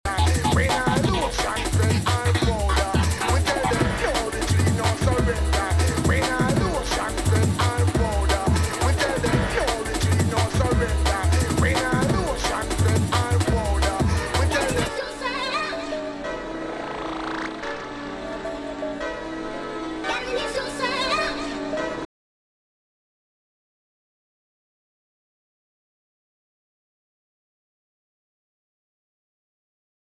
Drum & Bass / Jungle